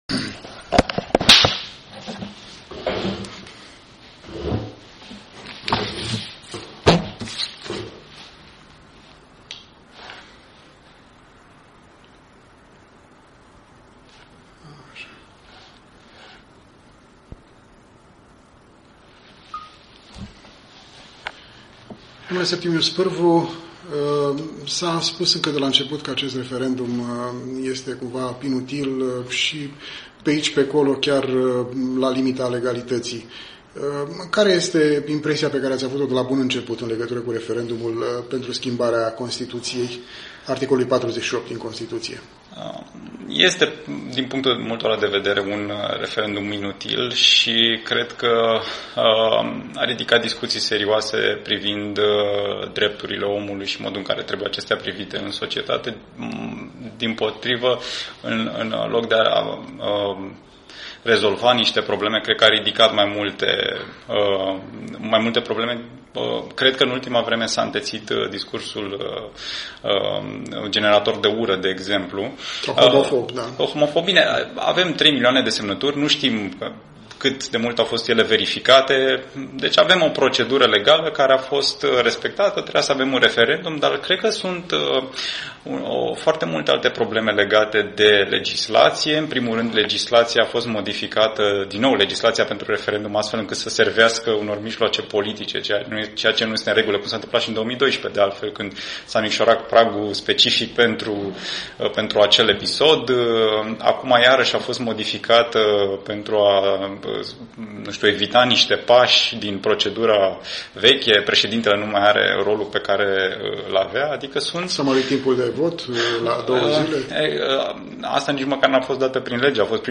Un interviu cu un observator al referendumului din România, din partea organizației Expert Forum.